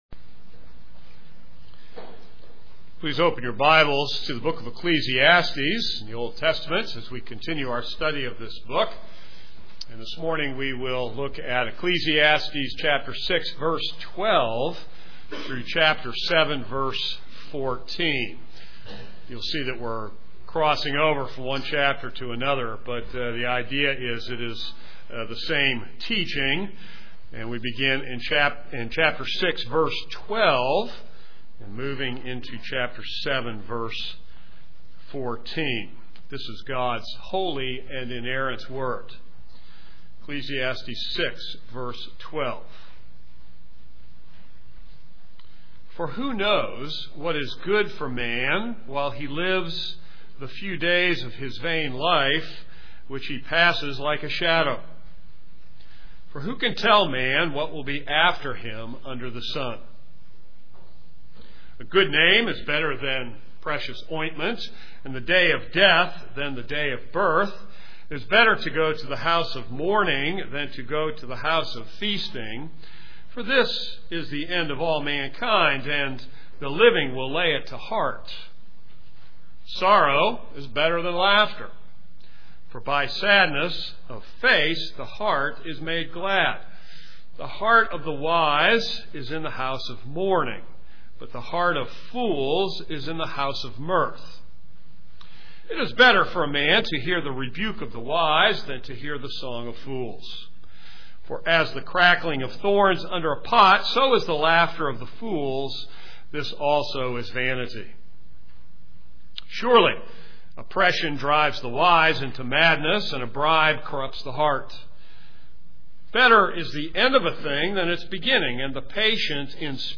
This is a sermon on Ecclesiastes 6:12-7:14.